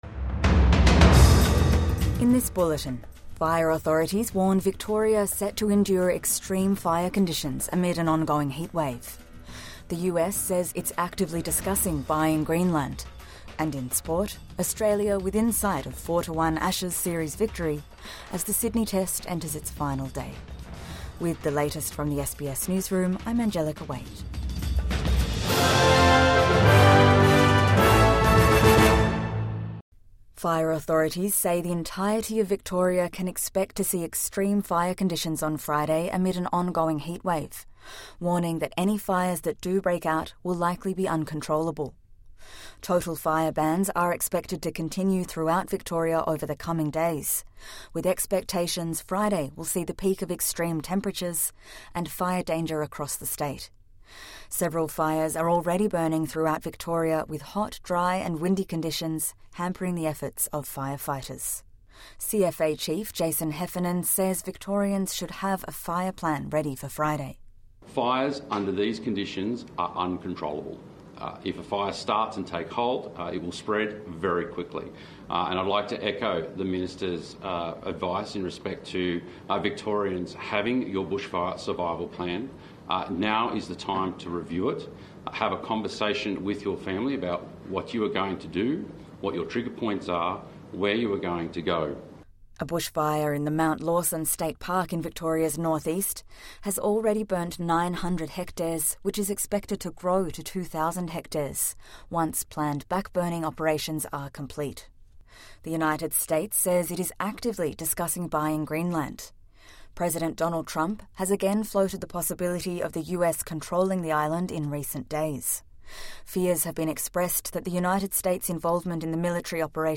The US says it's actively discussing buying Greenland | Morning News Bulletin 8 January 2026